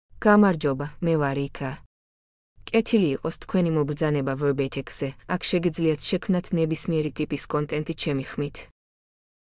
Eka — Female Georgian AI voice
Eka is a female AI voice for Georgian (Georgia).
Voice sample
Listen to Eka's female Georgian voice.
Eka delivers clear pronunciation with authentic Georgia Georgian intonation, making your content sound professionally produced.